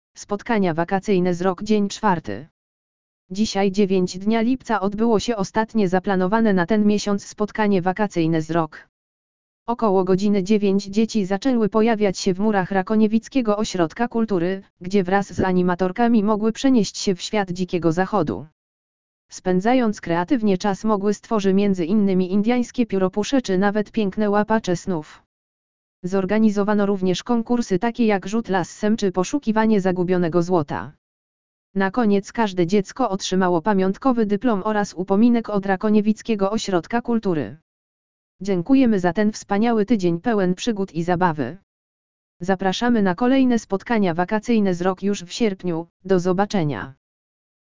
lektor_audio_spotkania_wakacyjne_z_rok_dzien_czwarty.mp3